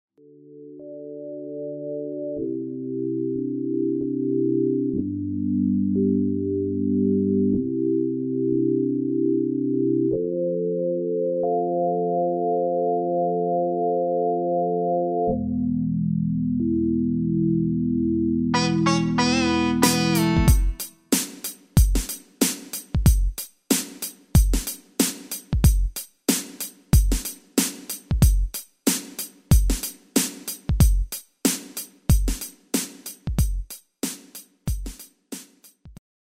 Basic MIDI File Euro 8.50
Demo's zijn eigen opnames van onze digitale arrangementen.